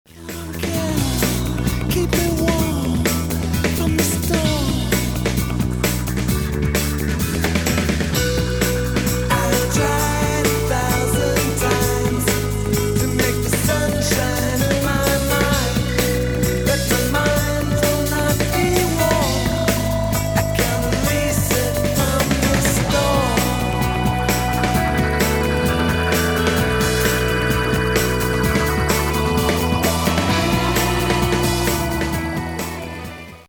performed with a set of original punk rock songs in 1978
vocals
guitar
bass
drums